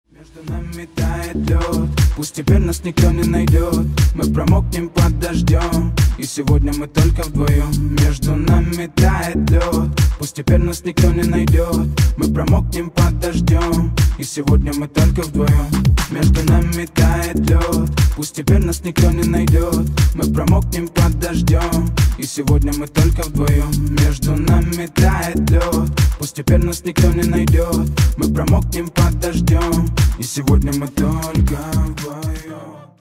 Рэп рингтоны